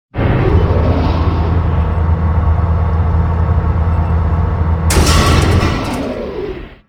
repair1.wav